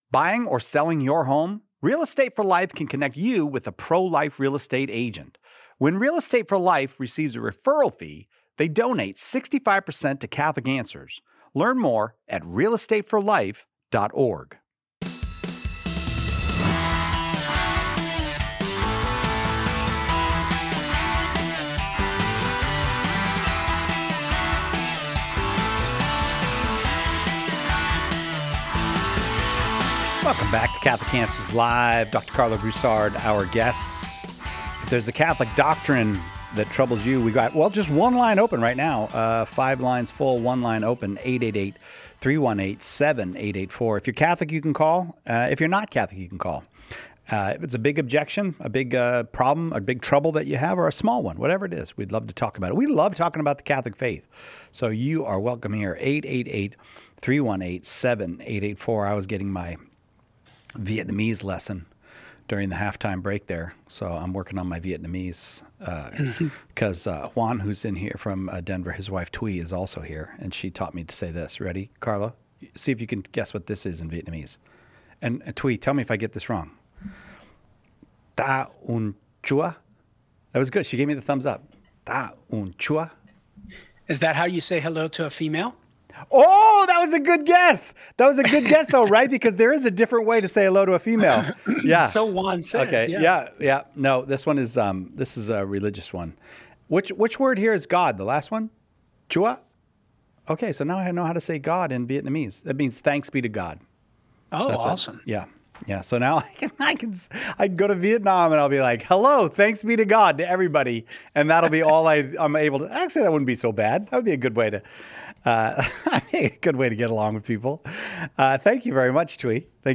A caller asks whether Muslims truly worship the same God as Christians.